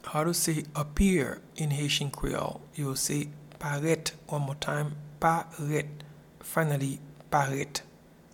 Pronunciation and Transcript:
Appear-in-Haitian-Creole-Paret.mp3